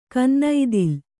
♪ kannaidil